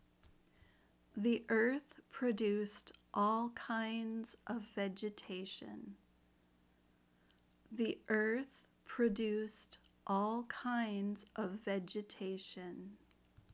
If you are learning American English, imitate her pronunciation the best you can.